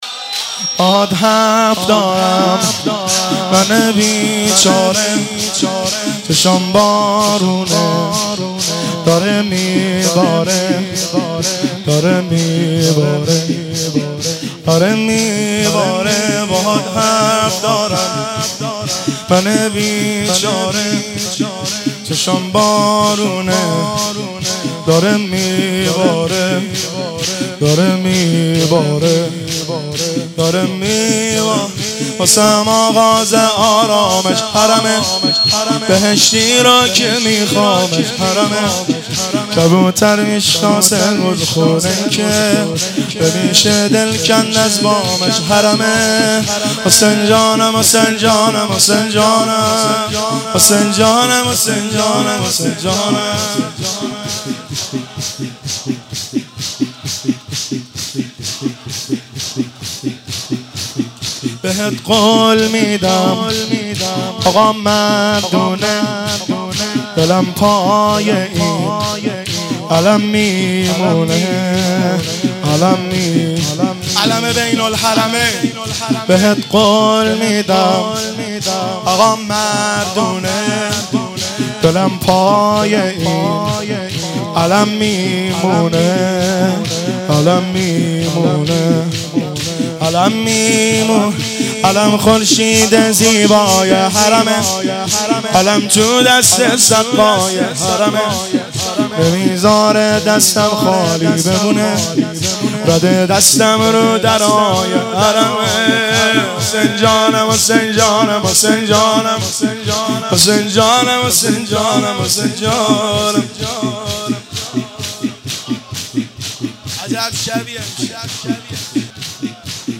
مجموعه نوحه های جلسه هفتگی ۹۶/۱۲/۰۹
که در بین الحرمین تهران اجرا شده است
شور